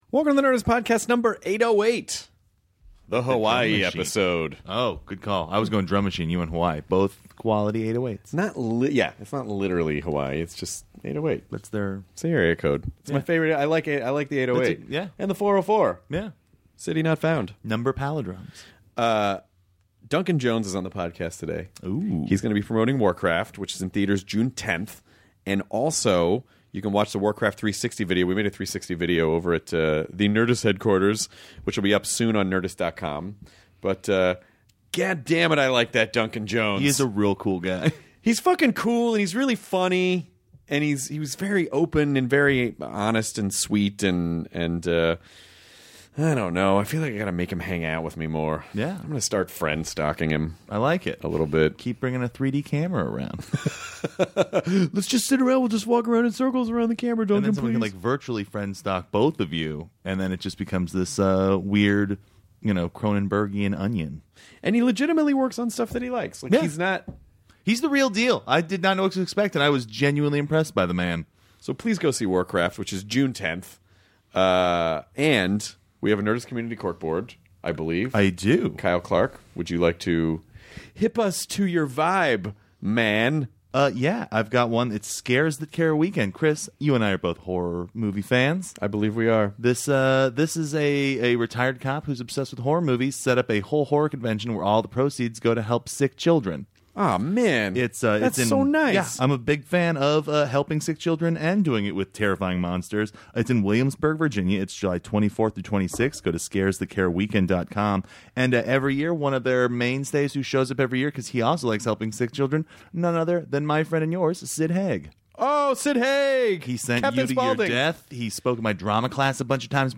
Duncan Jones (director, Moon, Warcraft) chats with Chris about how the physical world of Warcraft came alive, what he learned by going to film school and the time he did a commercial for the UK Singled Out. They also talk about working on Moon, the recent passing of his father and the responsibilities of being a director. They also come up with ideas for horror films made with babies!